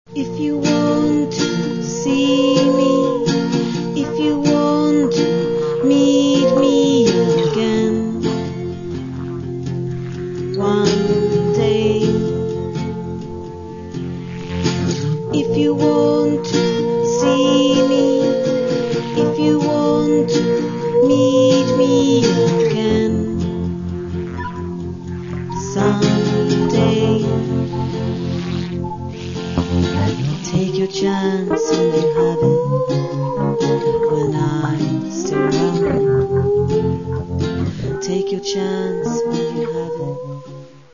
Каталог -> Рок и альтернатива -> Электронная альтернатива